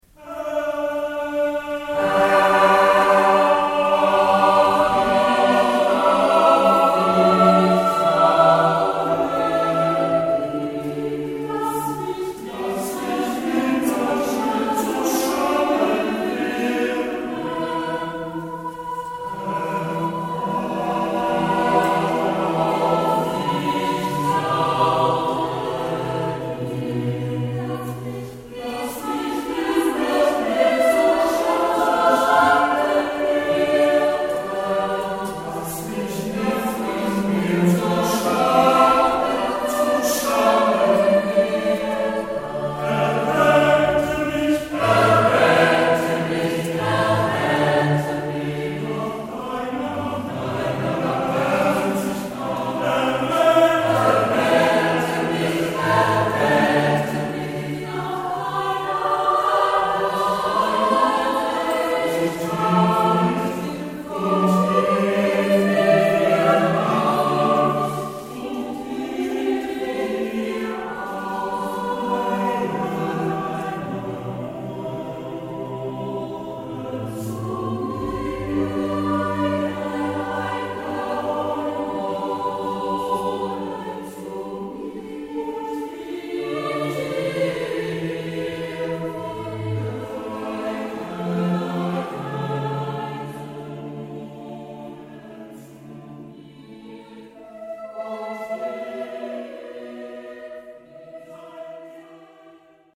Hörprobe: "Herr auf dich traue ich" von Heinrich Schütz, Konzertmitschnitt 2014